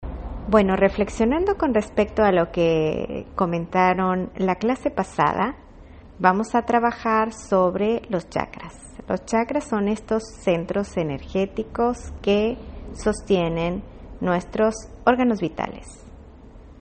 • Campo de sonido: Mono
Los estuve utilizando para entrevistas en exteriores y para videos utilizando un smartphone. Aquí les puedo comparto algunas voces que hemos capturado para que vean la calidad de los micrófonos.